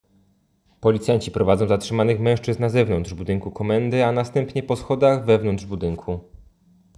Opis nagrania: Audiodeskrypcja filmu